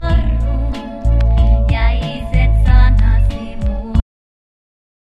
Parametric equalizer
low band corner frequency 100 Hz, low band gain +10 dB, mid band center frequency 400 Hz, mid band bandwidth 200 Hz, mid band gain -20 dB, high band corner frequency 5000 Hz, high band gain -20 dB